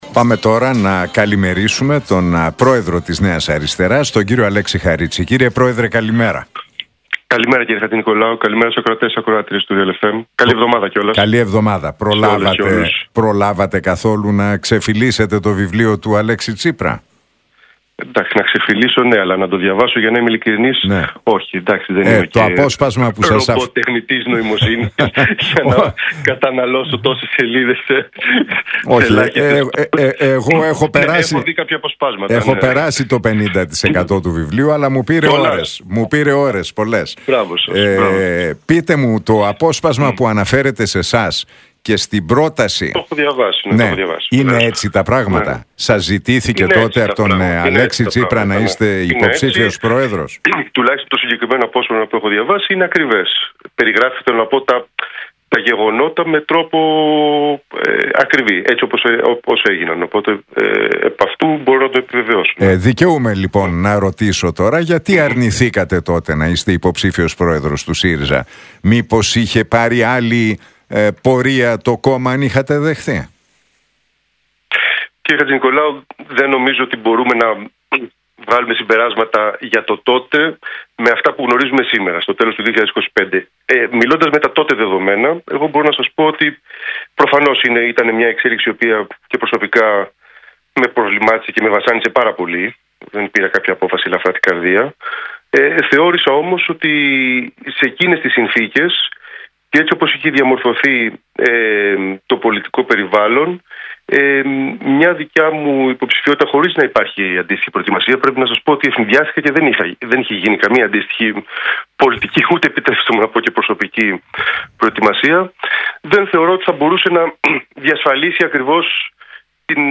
Για το βιβλίο «Ιθάκη» του Αλέξη Τσίπρα και την αποκάλυψη ότι ο πρώην Πρωθυπουργός του είχε προτείνει να είναι υποψήφιος πρόεδρος του ΣΥΡΙΖΑ μίλησε ο πρόεδρος της Νέας Αριστεράς, Αλέξης Χαρίτσης στον Νίκο Χατζηνικολάου από τη συχνότητα του Realfm 97,8.